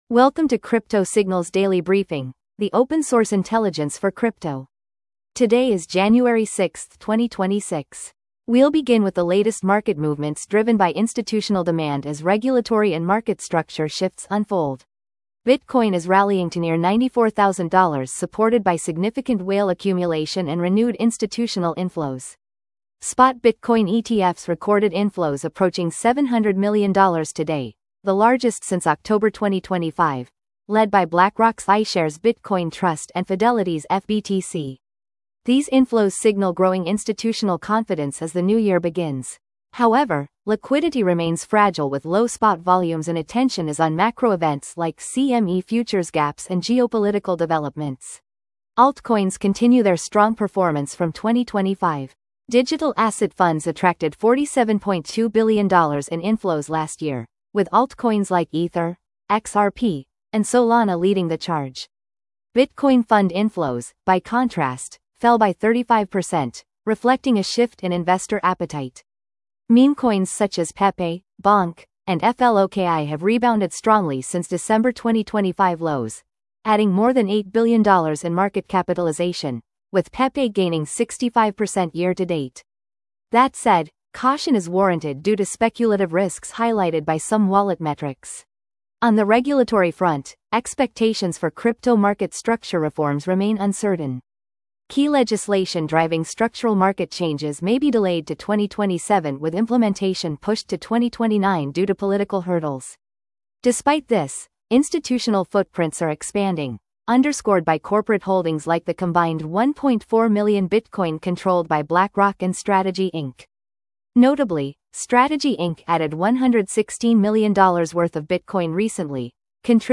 Crypto Signals Daily market brief